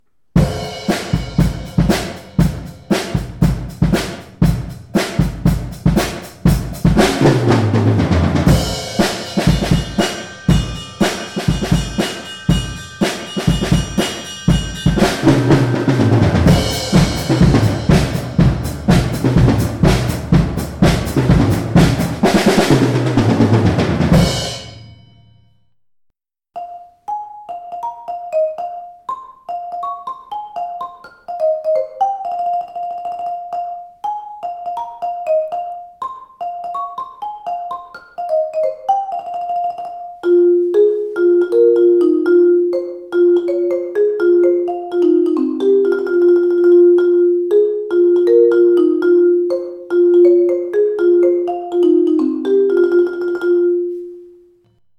Drum Set / Schlagwerk:
Neben der großen und der kleinen Trommel und den Becken, die dafür sorgen, dass jeder Musikant im richtigen Schritt marschiert, gibt es noch Stabspiele (z.B. das Xylophon auf der Aufnahme) und viele kleine Instrumente für Effekte.
Drum-Set_Xylo.mp3